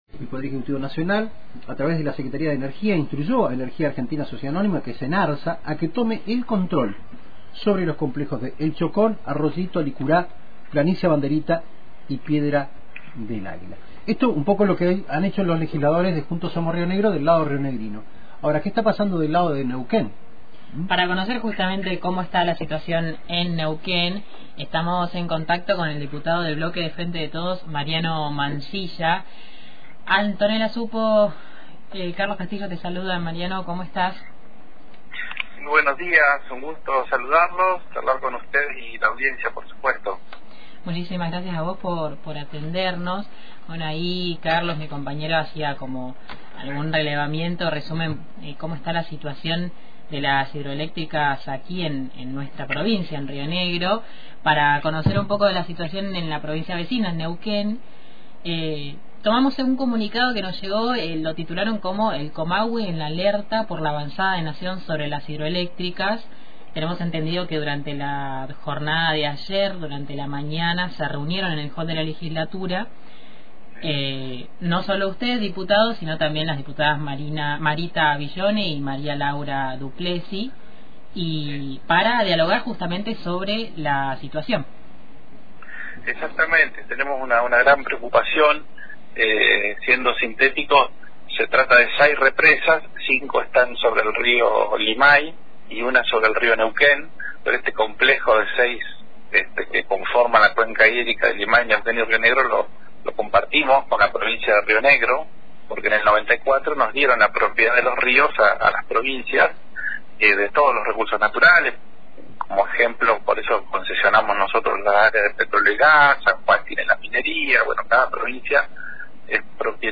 Así lo manifestó el diputado Neuquino del Frente de Todos, Mariano Mansilla, en diálogo con radio Antena Libre, al referirse a la negativa de Nación para dar participación activa a las provincias de Rio Negro y Neuquén en el manejo del complejo de represas.